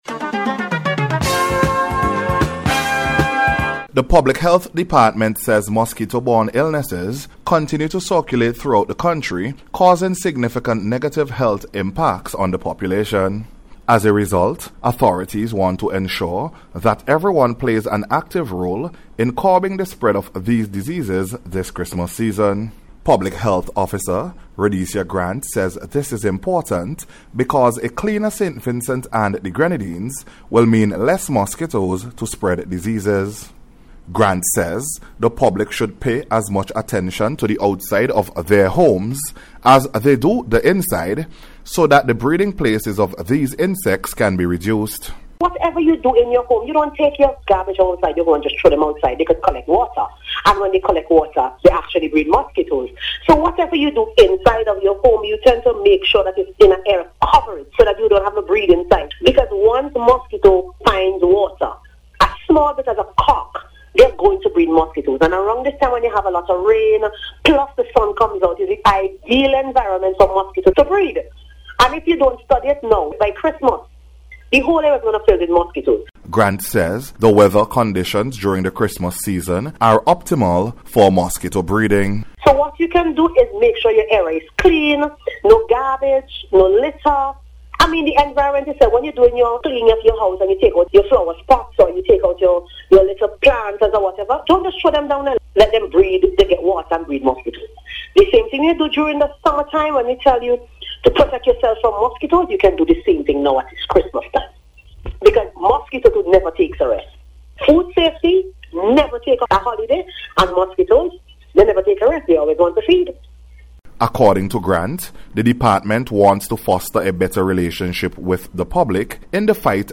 AVOIDING-MOSQUITO-RELATED-ILLNESSES-AT-CHRISTMAS-REPORT.mp3